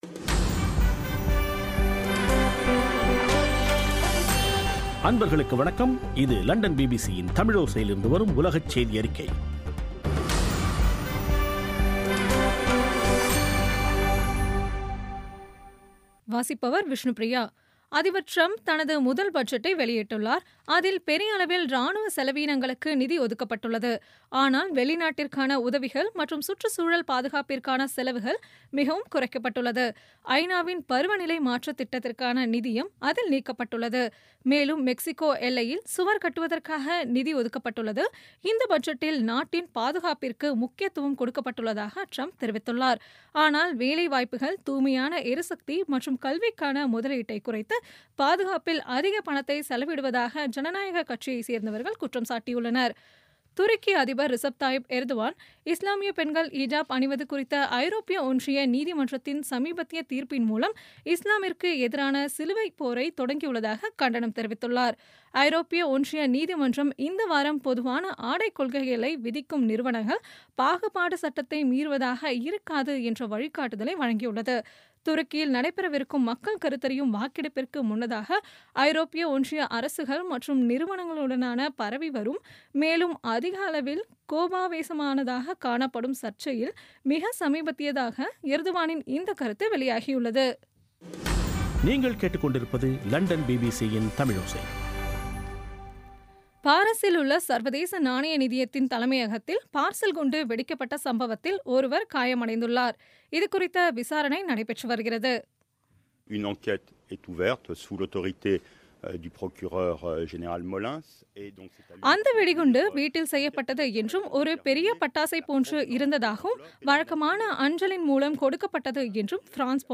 பிபிசி தமிழோசை செய்தியறிக்கை (16/03/2017)